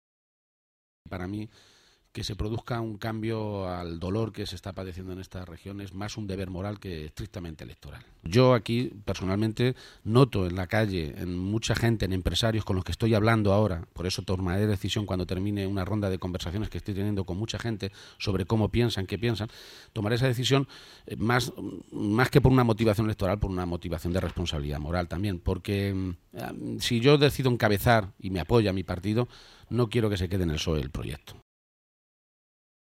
García-Page se ha pronunciado de esta manera esta mañana, durante una entrevista en el programa La Mañana de la Cadena Cope, conducido por Ángel Expósito, cuando se le ha preguntado por el momento en el que anunciará su candidatura a la Presidencia de la Junta de Comunidades de Castilla-La Mancha.
Cortes de audio de la rueda de prensa